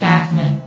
New & Fixed AI VOX Sound Files